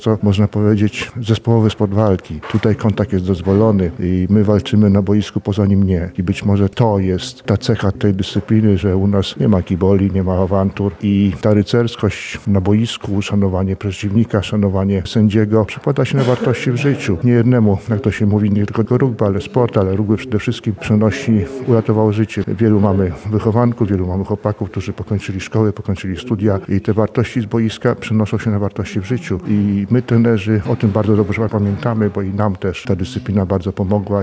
Bezpłatne konsultacje dla mieszkańców Lublina Spotkanie zorganizowane w Centrum Historii Sportu w Lublinie wpisuje się w obchody Ogólnopolskiego Dnia Seniora.